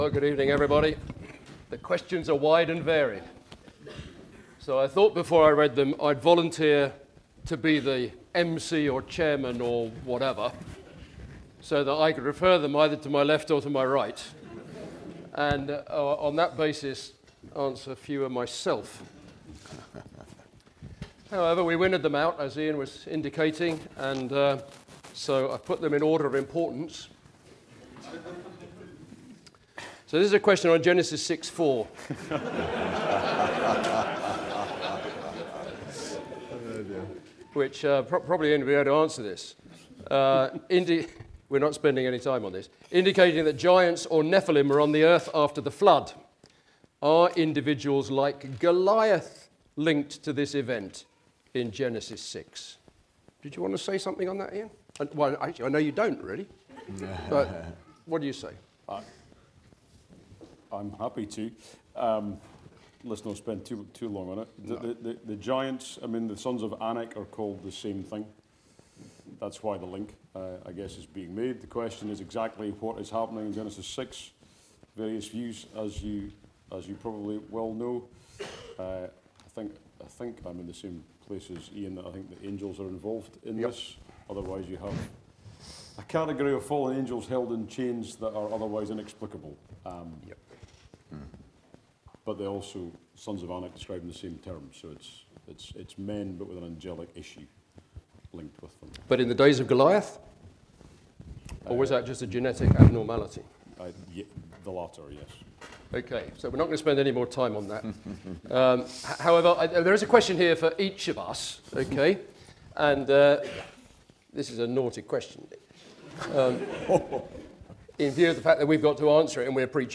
Questions and Answers (100 mins)
6-Panel-Your-Questions-Answered.mp3